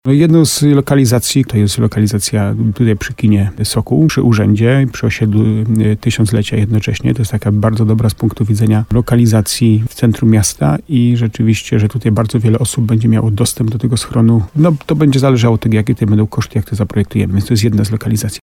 Burmistrz Jacek Lelek informuje, że są już potencjalne lokalizacje dla takiej inwestycji.